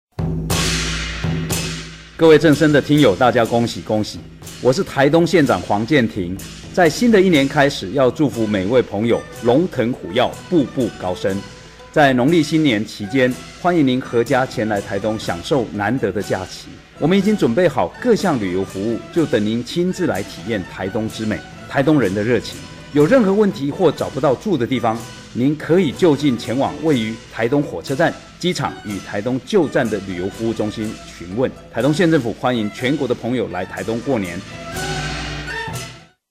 台東台專訪台東縣長黃健庭
台東縣長黃健庭向全國正聲的聽友拜年